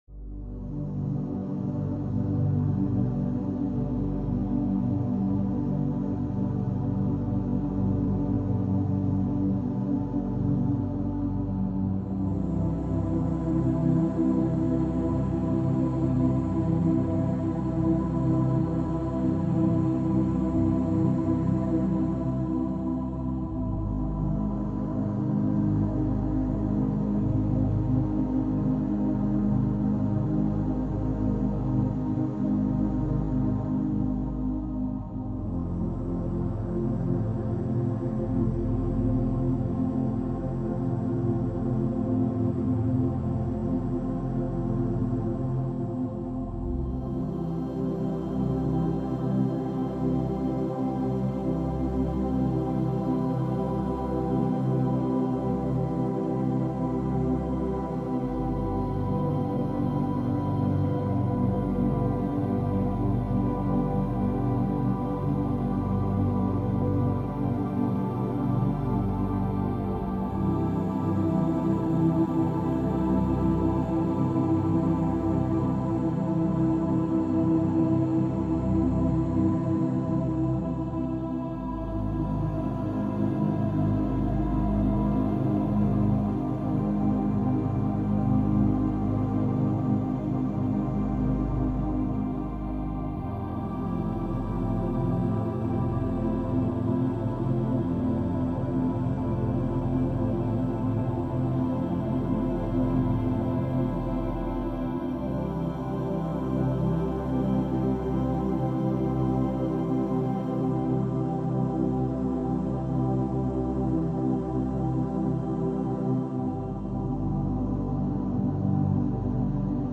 6hz - Theta Binaural Beats for Clarity ~ Binaural Beats Meditation for Sleep Podcast